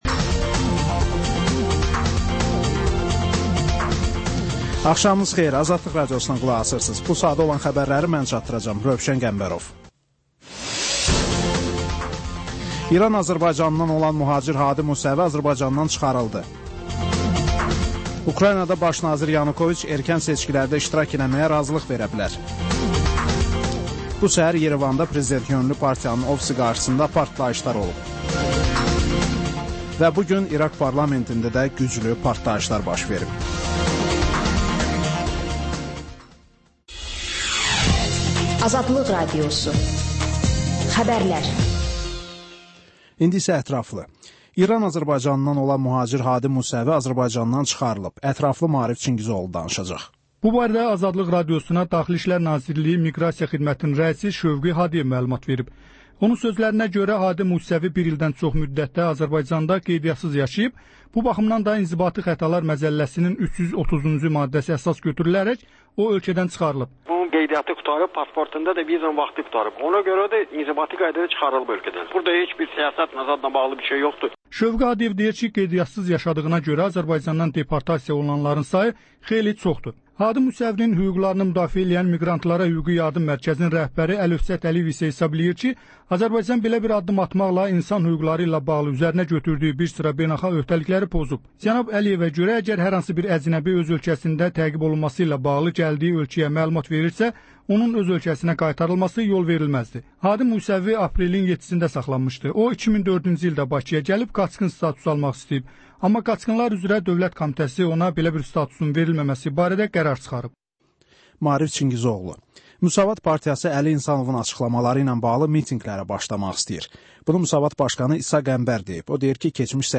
Xəbərlər, müsahibələr, hadisələrin müzakirəsi, təhlillər, sonra TANINMIŞLAR rubrikası: Ölkənin tanınmış simalarıyla söhbət